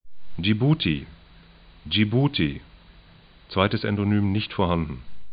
ʤi'bu:ti